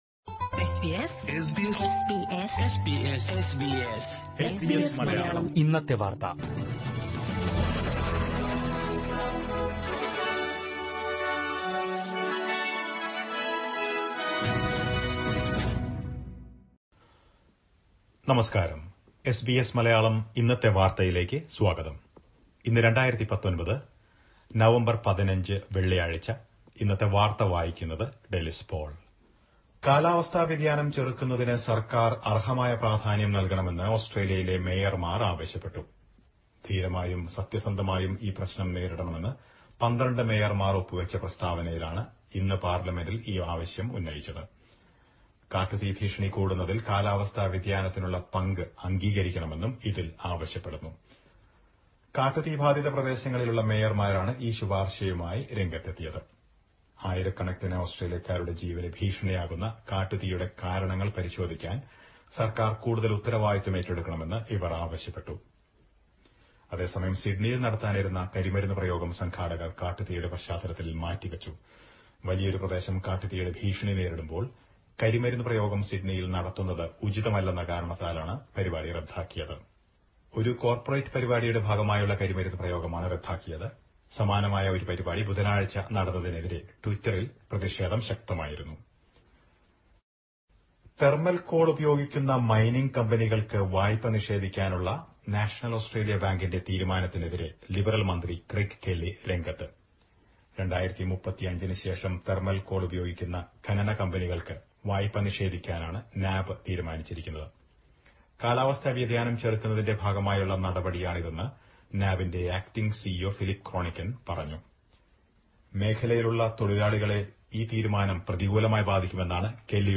2019 നവംബര്‍ 15ലെ ഓസ് ട്രേലിയയിലെ ഏറ്റവും പ്രധാന വാര്‍ത്തകള്‍ കേള്‍ക്കാം